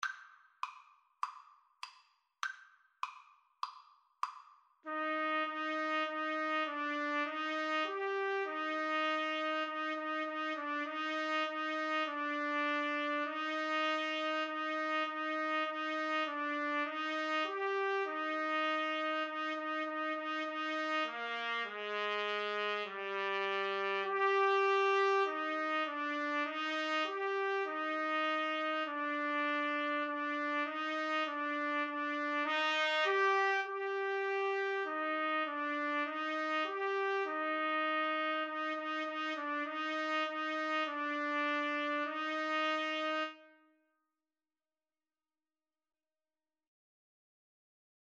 4/4 (View more 4/4 Music)